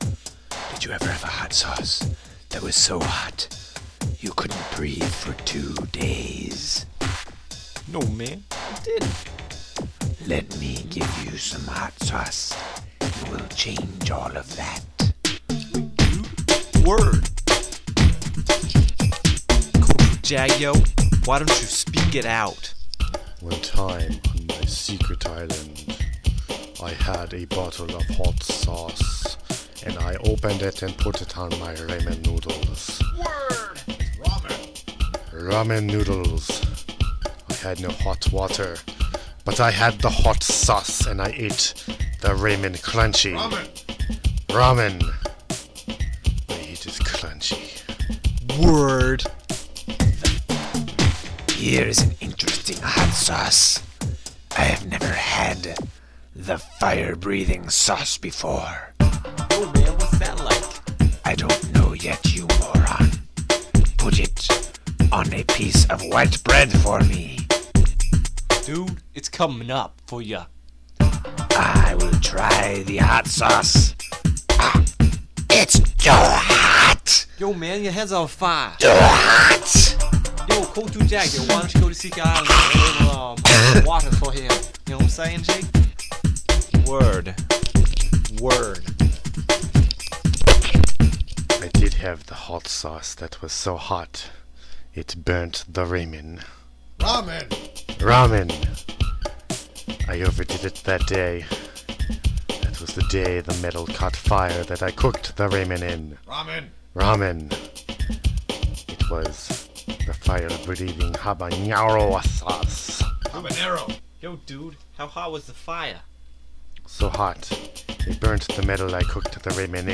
He also creates the remixes from nothing other than random sounds and sine waves.
He concentrates on masterful lyrics and unstructured prose.